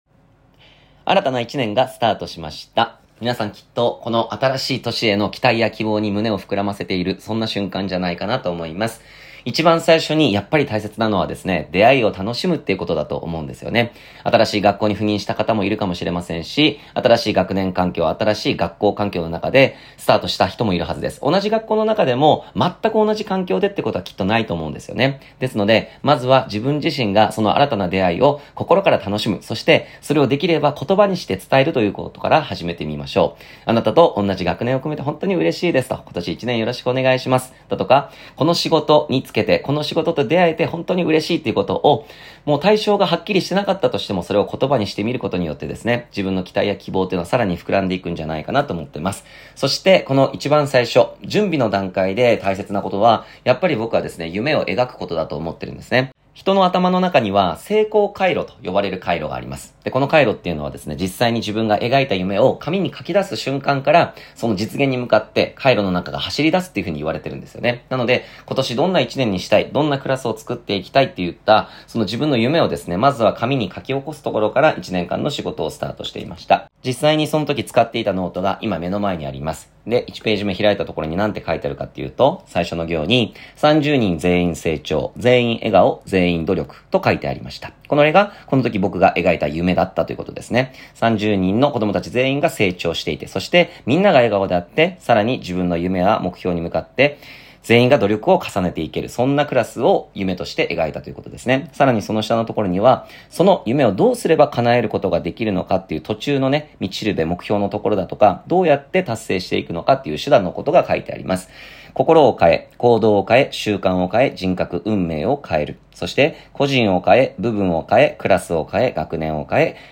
【音声メッセージ】サンプル